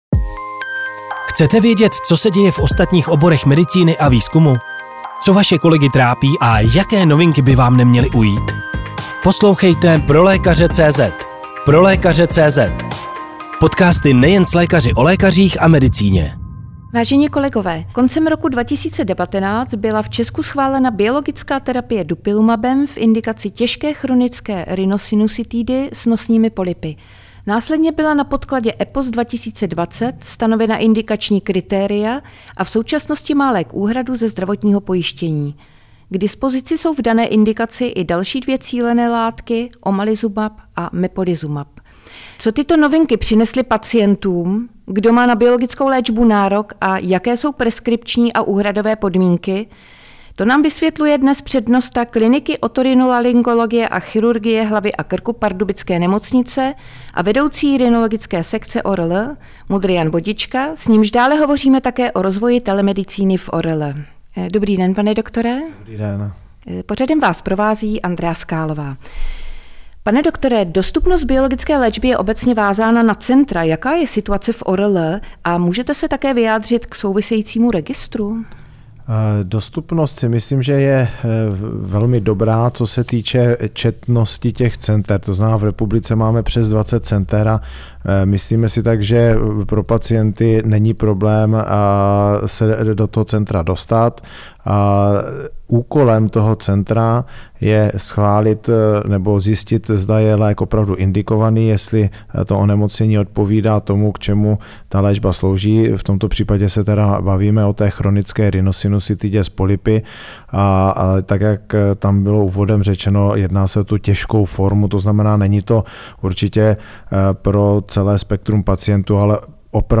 V další části rozhovoru potom diskutujeme mimo jiné o souvisejícím rozvoji telemedicíny v ORL.